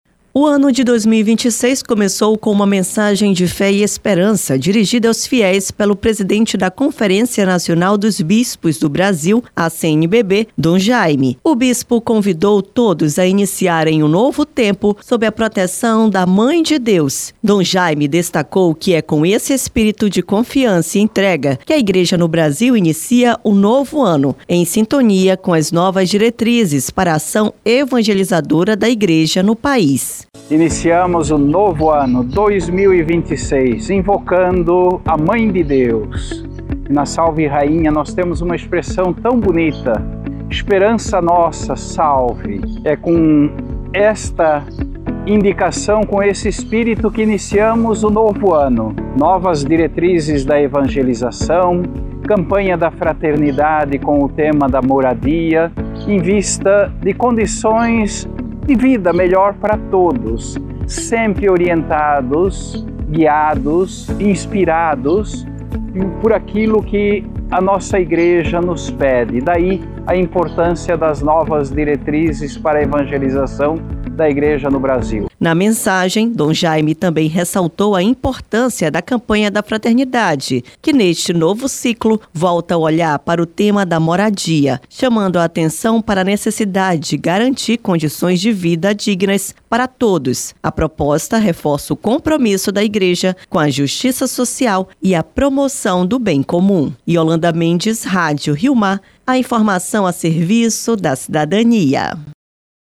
O Ano Novo de 2026 teve início com uma mensagem de fé e esperança dirigida aos fiéis pelo presidente da Conferência Nacional dos Bispos do Brasil (CNBB), dom Jaime Spengler.